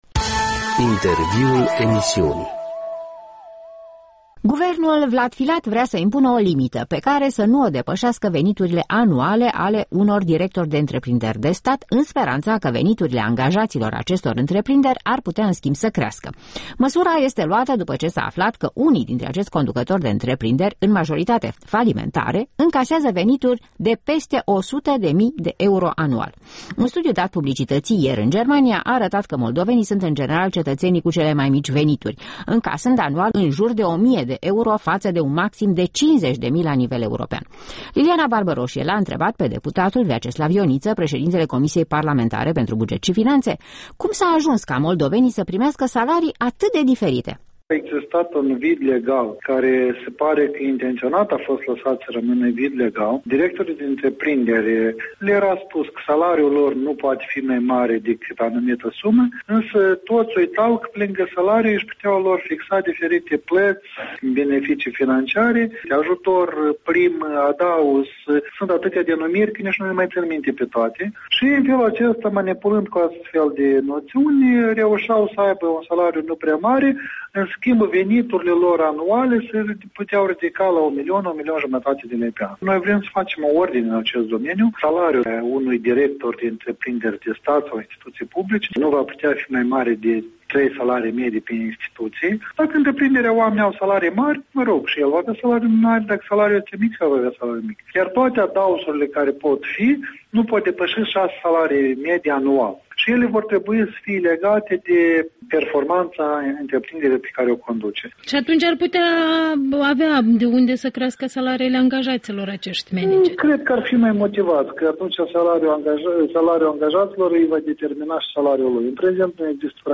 Un interviu cu Veaceslav Ioniță despre legislația salariilor angajaților de stat